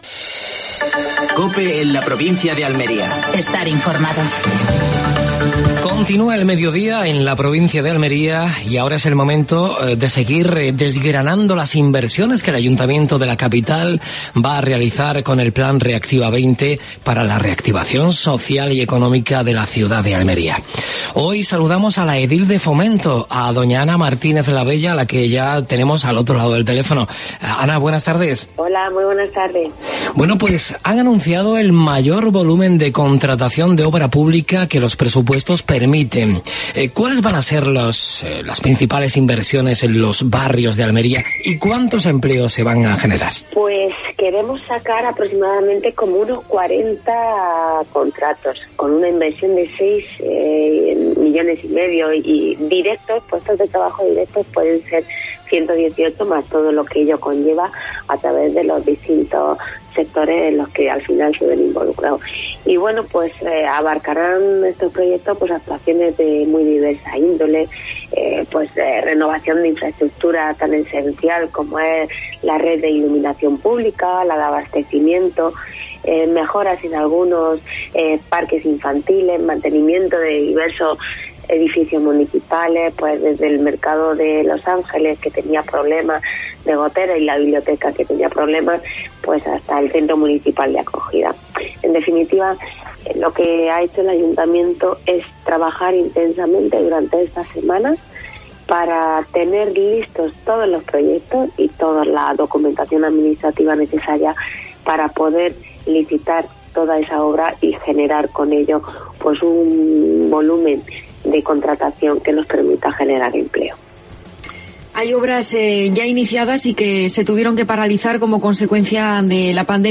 AUDIO: Actualidad en Almería. Entrevista a Ana Martínez Labella (concejal de Fomento del Ayuntamiento de Almería).